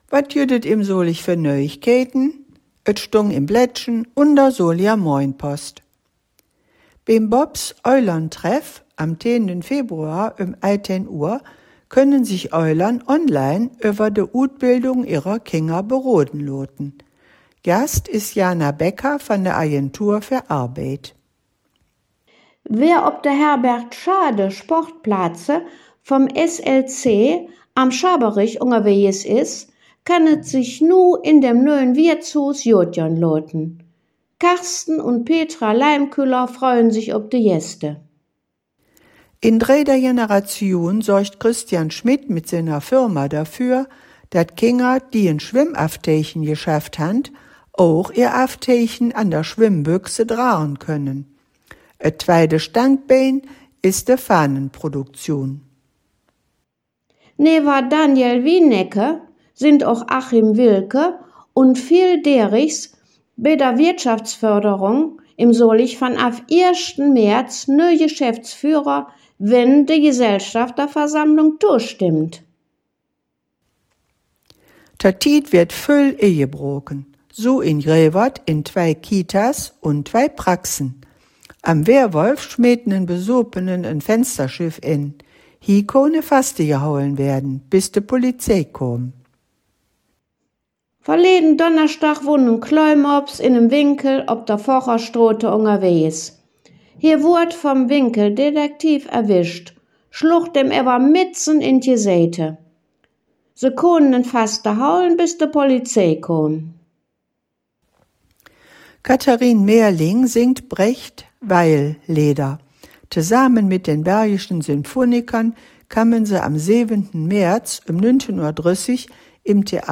Der in Solingen gesprochene Dialekt wird Solinger Platt genannt.
Zugleich wird im Solinger Platt aber auch die Nähe zum ripuarischen Sprachraum (vor allem durch das Kölsch bekannt) hörbar.